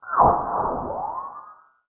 WATER STAB.wav